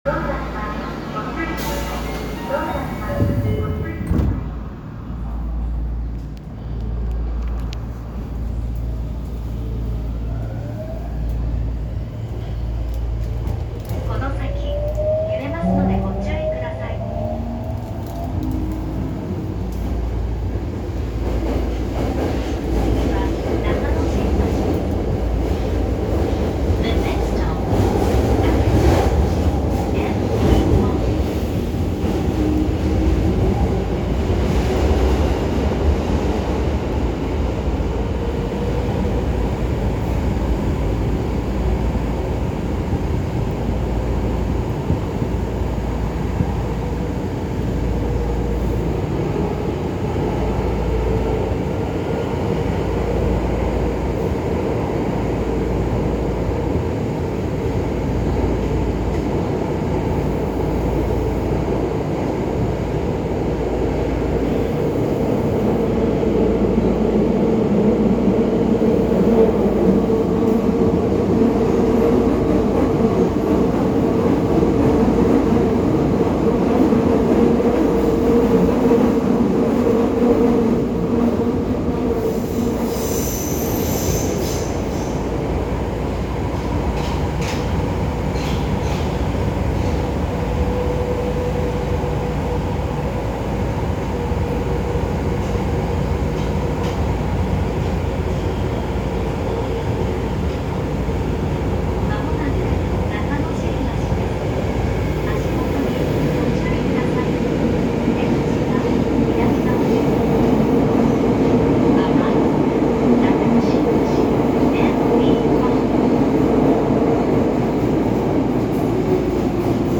・02系三菱IGBT車走行音
【方南町支線】中野坂上→中野新橋
三菱の初期のIGBTを採用している他の系列と基本的には同じ走行音です。第3軌条ゆえに音の聞こえ方が少々異なるのも他のモーターの車両と同様。